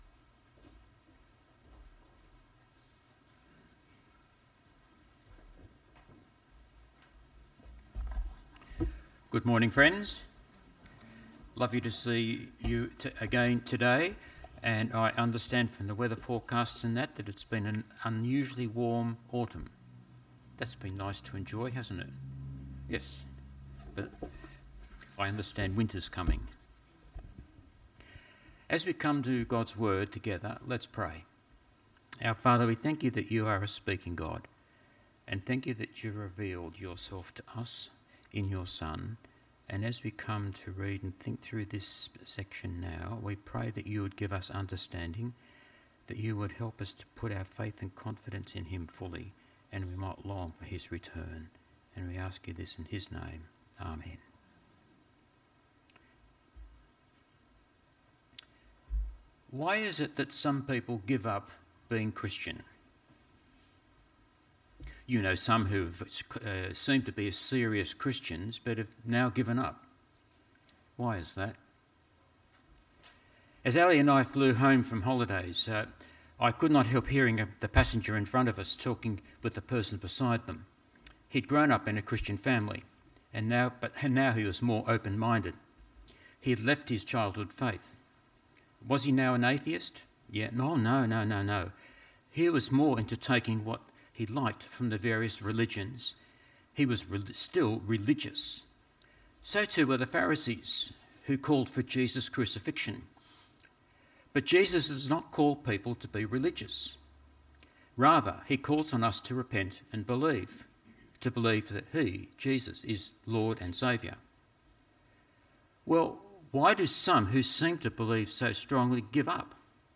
This sermon was preached on 1st June at all services of Helensburgh and Stanwell Park Anglican Church.